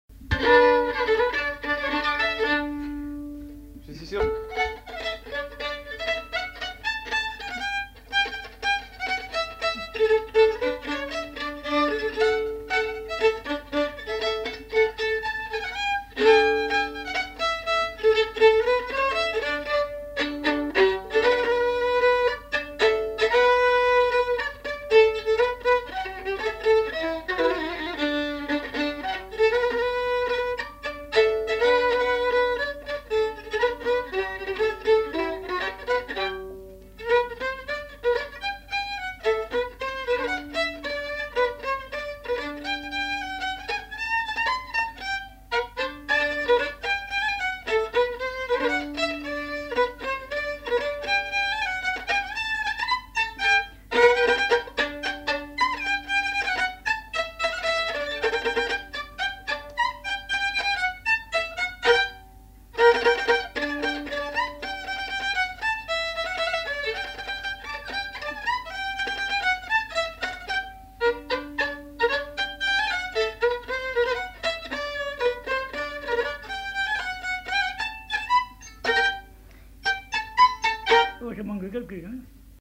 Aire culturelle : Lomagne
Genre : morceau instrumental
Instrument de musique : violon
Danse : polka